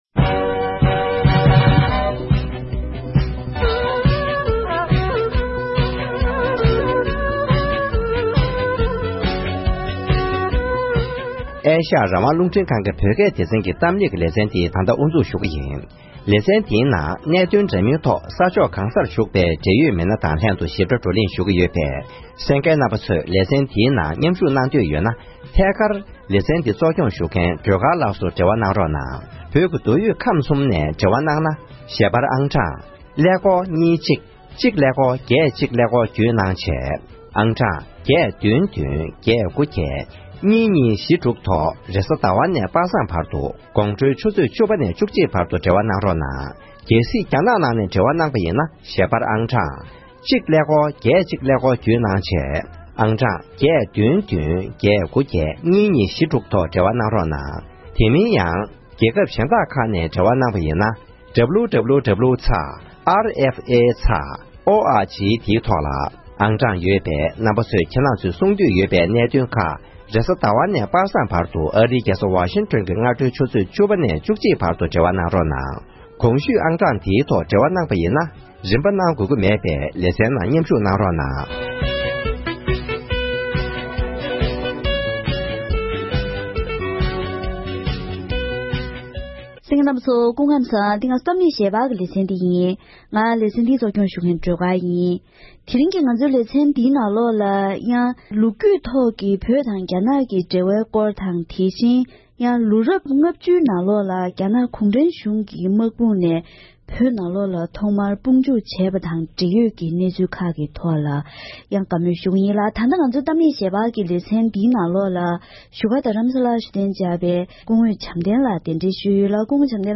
༄༅། །དེ་རིང་གི་གཏམ་གླེང་ཞལ་པར་ལེ་ཚན་ནང་རྒྱ་ནག་གཞུང་གིས་བོད་འདི་གནའ་སྔ་མོ་ནས་རྒྱ་ནག་གི་མངའ་ཁོངས་ཡིན་པའི་འཁྱོག་བཤད་དང་ལོ་རྒྱུས་ལ་རྫུན་བཟོ་བྱེད་བཞིན་ཡོད་པས། ལོ་རྒྱུས་ཐོག་བོད་དང་རྒྱ་ནག་གི་འབྲེལ་བའི་ཐོག་དངོས་ཡོད་གནས་སྟངས་ཇི་ཡིན་སྐོར་ལོ་རྒྱུས་ལ་ཉམས་ཞིབ་གནང་མཁན་འབྲེལ་ཡོད་མི་སྣ་མཉམ་དུ་བཀའ་མོལ་ཞུས་པ་ཞིག་གསན་རོགས་གནང་།།